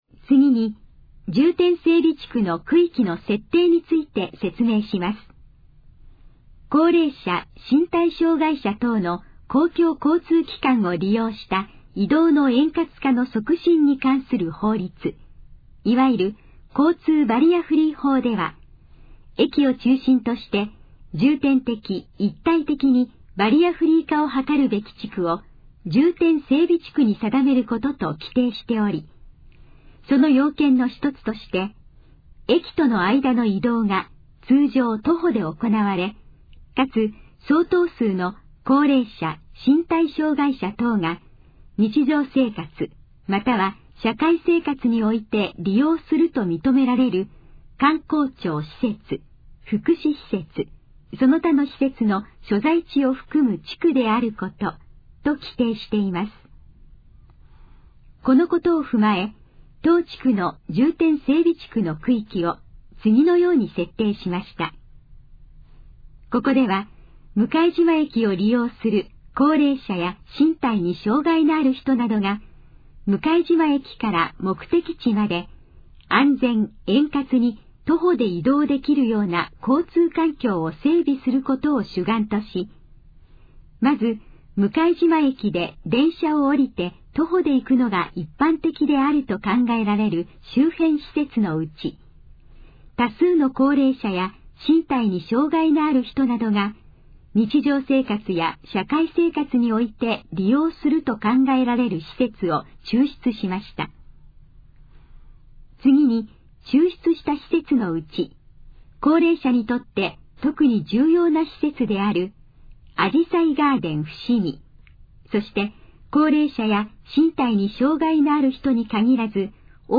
このページの要約を音声で読み上げます。
ナレーション再生 約313KB